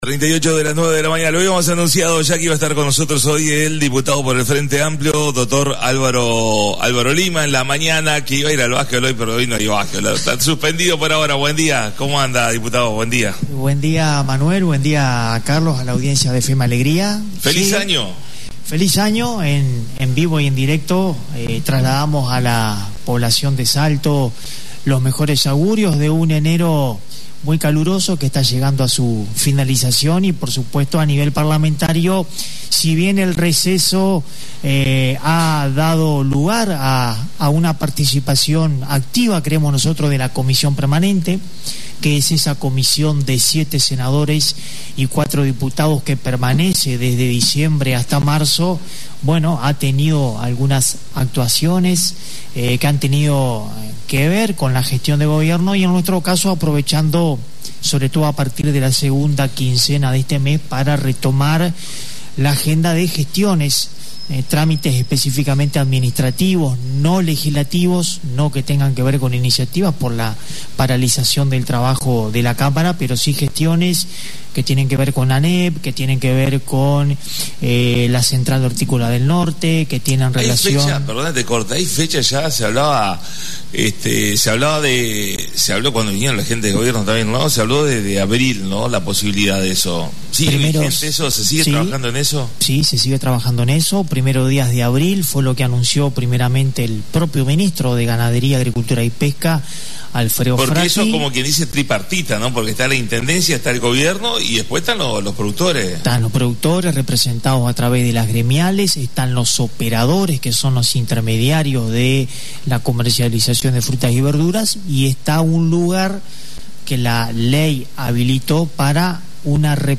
La visita a la Radio del Diputado por el Frente Amplio Dr Álvaro Lima